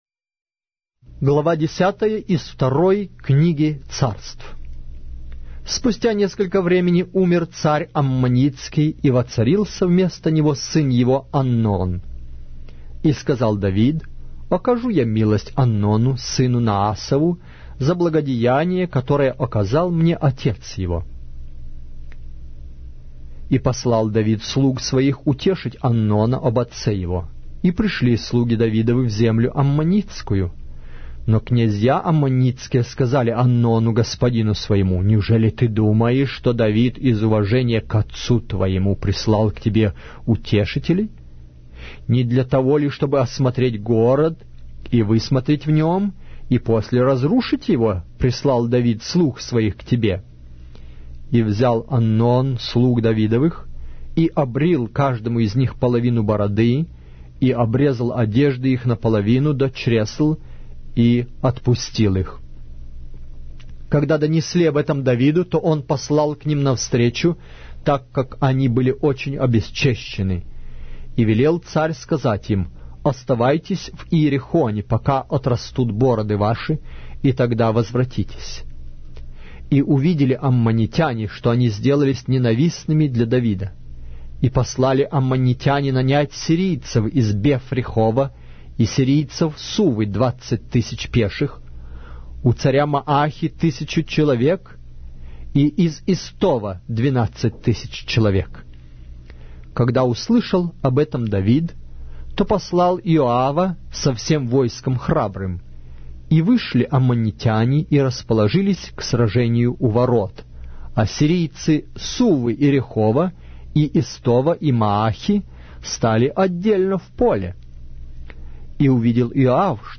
Аудиокнига: 2-я Книга Царств